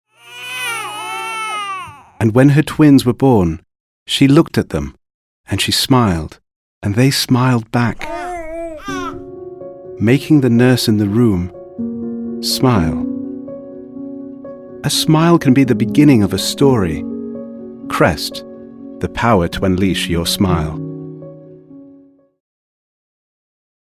calming, warm, velvety